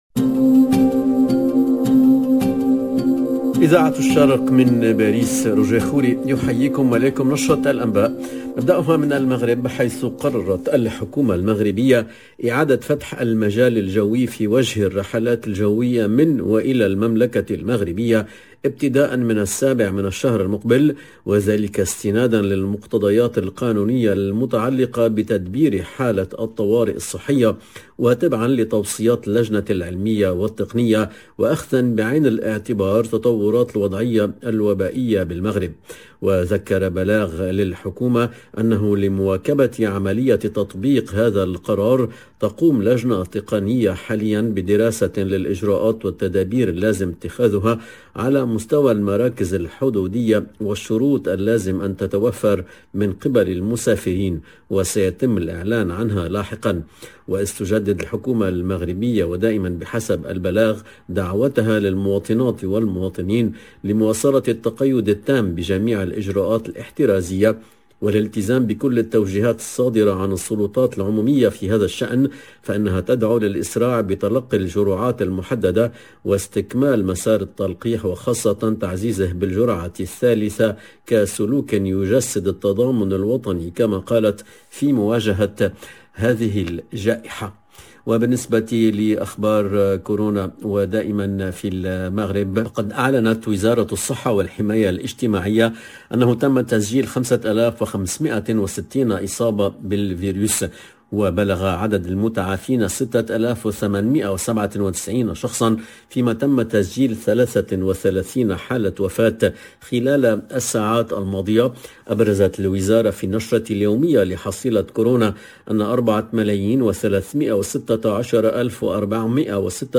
LE JOURNAL DE LA MI-JOURNEE EN LANGUE ARABE DU 28/01/22